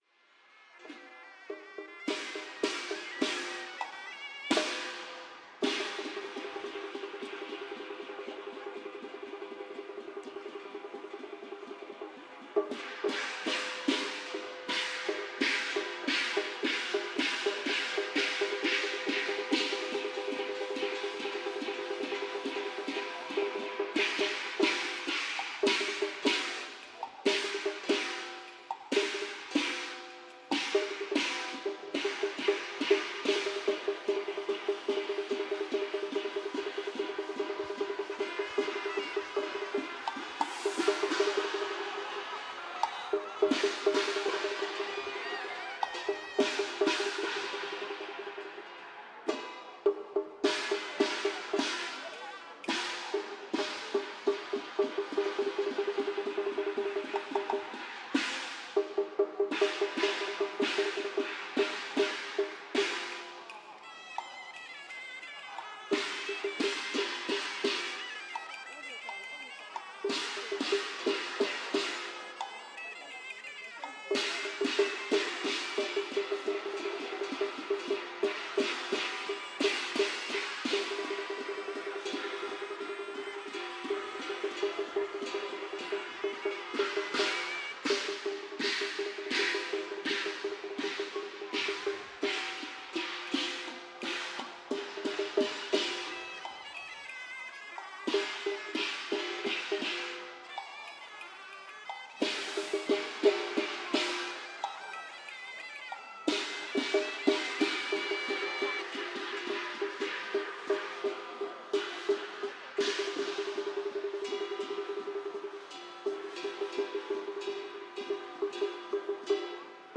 描述：这是从湾仔到中环的手推车顶层甲板的环境声。我周围说的一些语言不是中文（我相信在背景中会说塔加路语）。
声道立体声